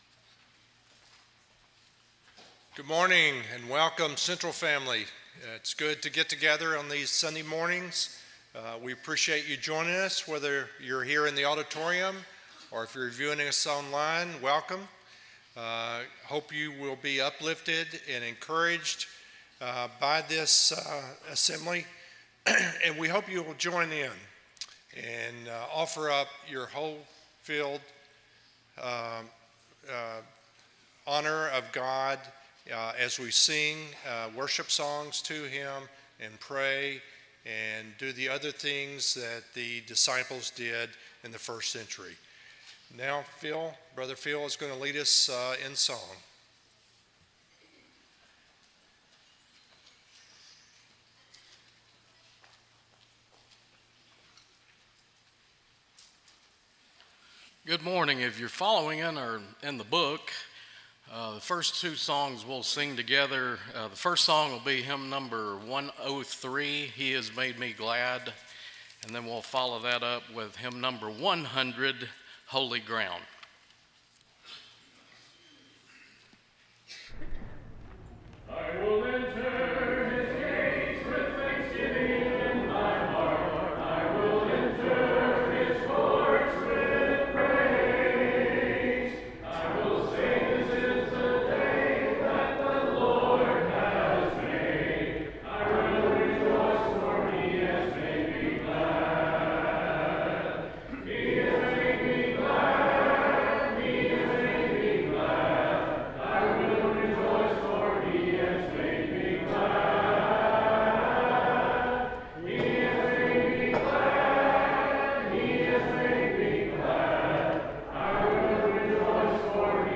Psalm 119:89, English Standard Version Series: Sunday AM Service